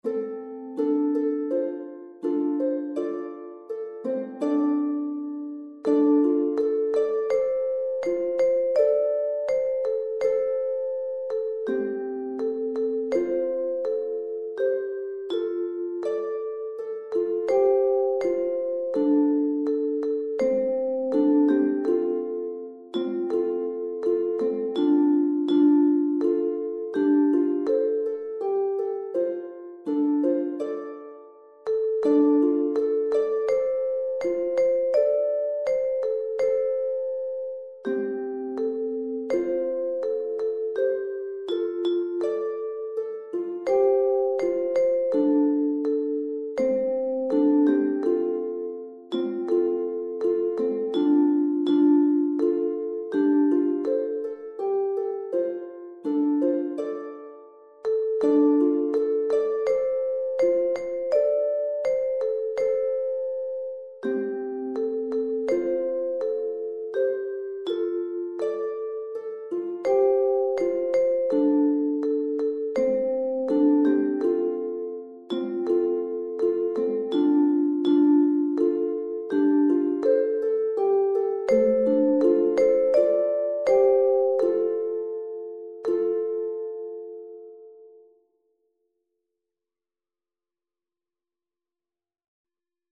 Met lierbegeleiding (4-tels)
dit liedje is pentatonisch